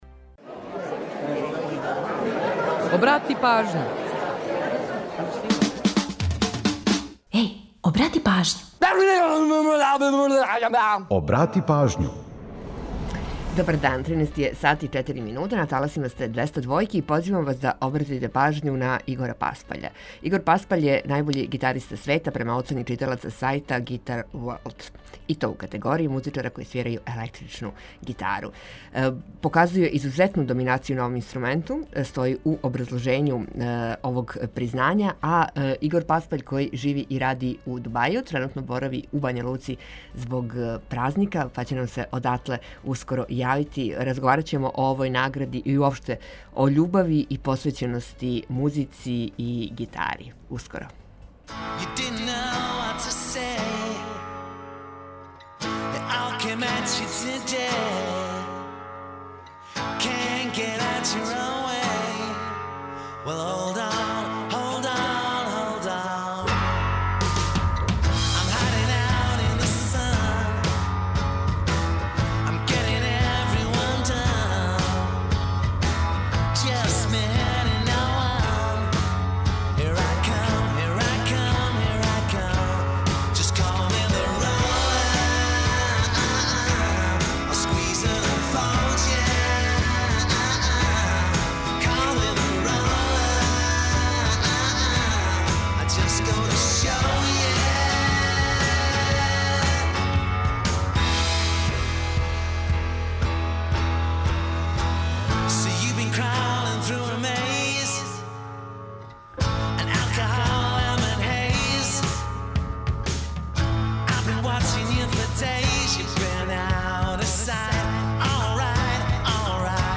Наставак емисије резервисан је за музичке приче: датумске песме подсећају нас на рођендане музичара као и годишњице објављивања албума, синглова и других значајних догађаја из историје попа и рокенрола. Ту је и пола сата резервисано само за музику из Србије и региона, а упућујемо вас и на нумере које су актуелне.